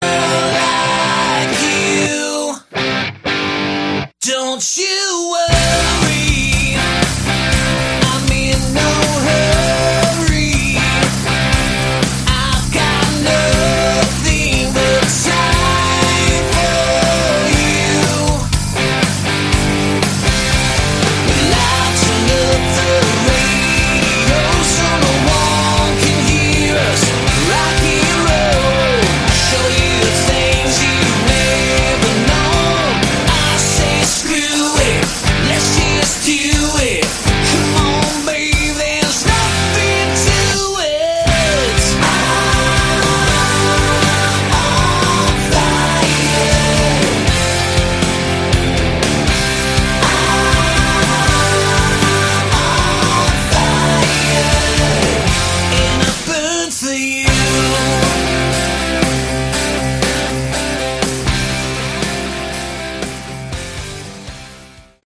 Category: Hard Rock
Lead & Backing Vocals, Guitars, Bass, Keyboards
Drums
Lead Guitar
Additional Backing vocals